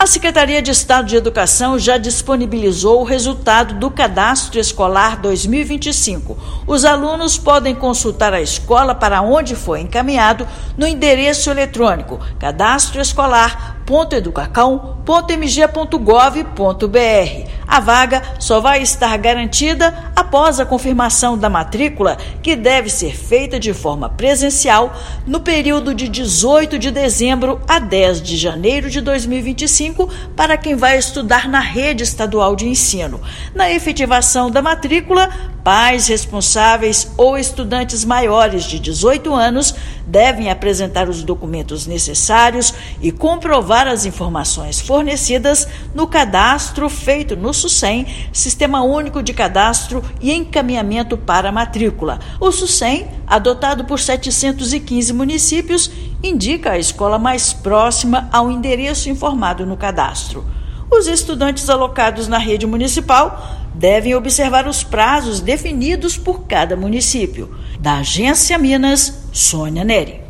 [RÁDIO] Educação divulga resultado do Cadastro Escolar 2025
Interessados agora devem confirmar a matrícula presencialmente para garantir vaga na rede pública de ensino. Ouça matéria de rádio.